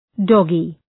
Shkrimi fonetik {‘dɒgı}